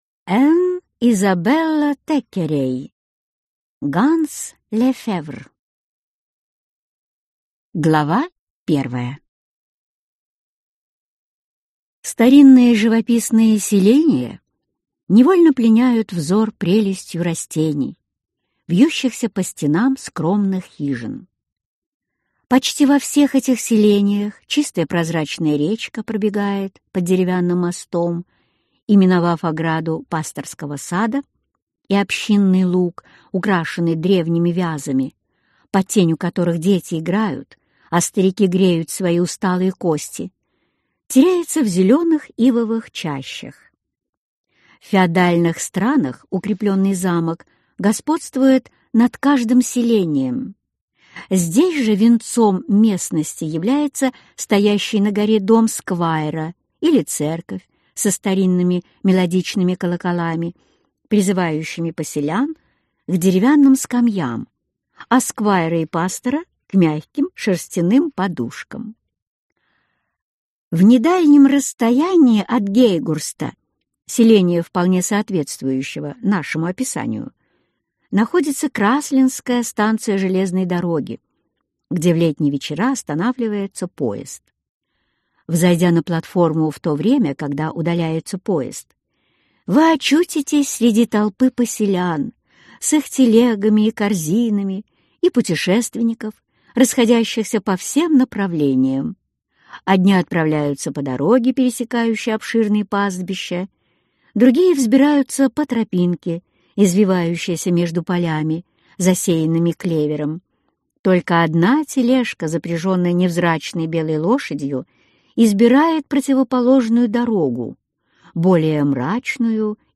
Аудиокнига Ганс Лефевр | Библиотека аудиокниг